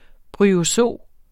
Udtale [ bʁyoˈsoˀ ]